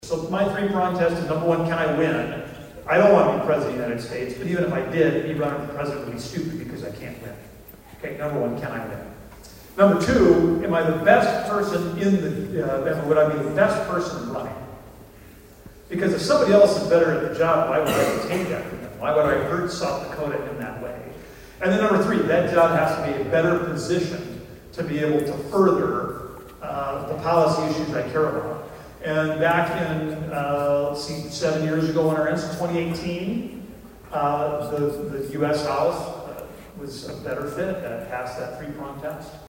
ABERDEEN, S.D.(HubCityRadio)- Monday kicked off the 82nd Boys State taking place at Northern State University in Aberdeen.